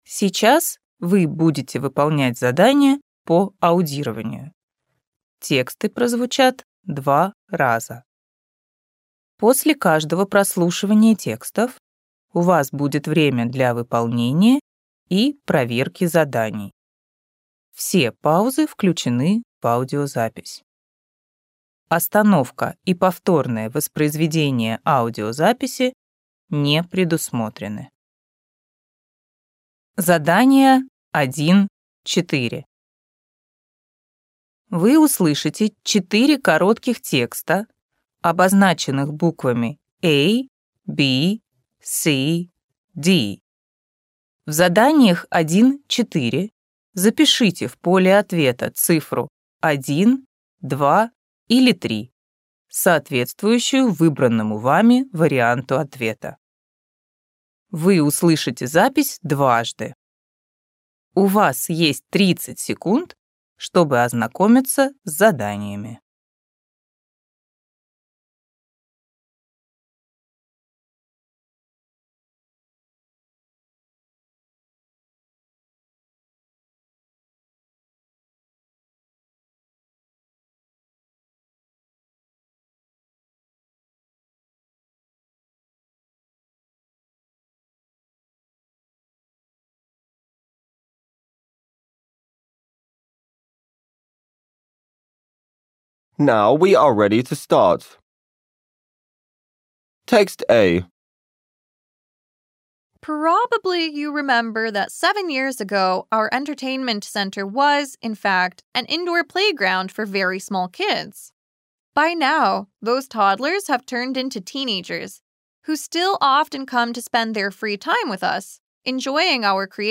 Раздел 1 (задания по аудированию)